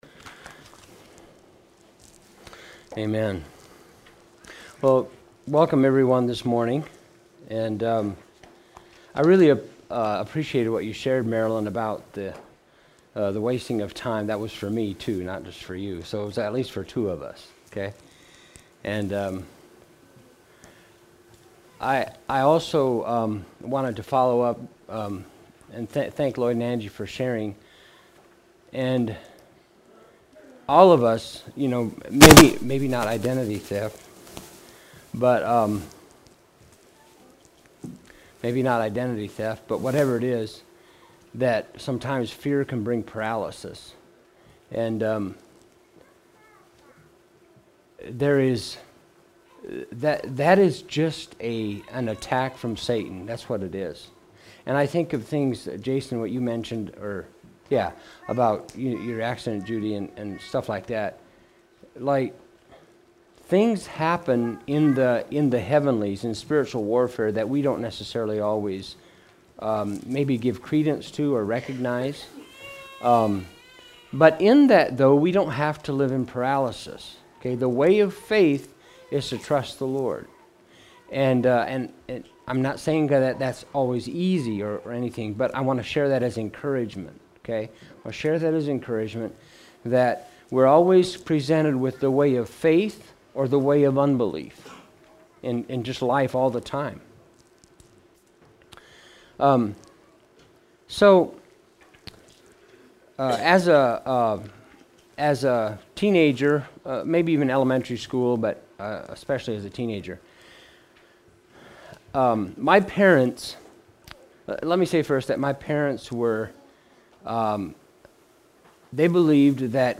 Home Sermons How Shall We Do The Work God Requires?